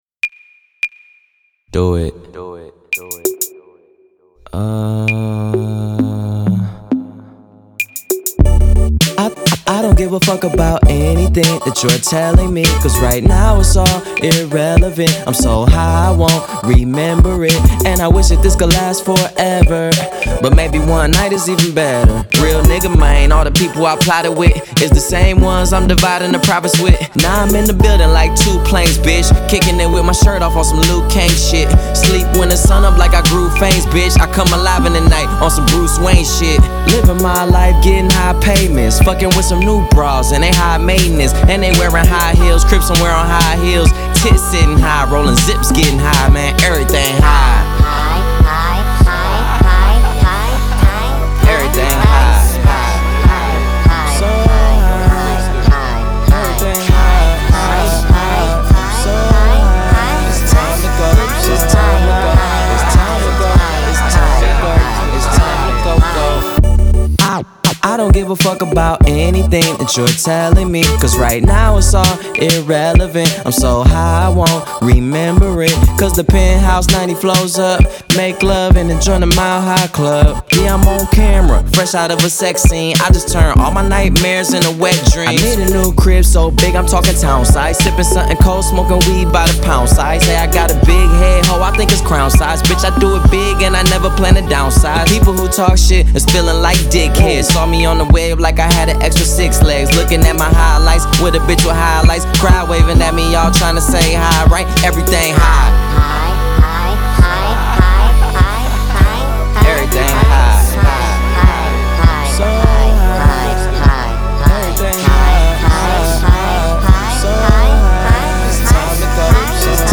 Genre : Rap, Hip Hop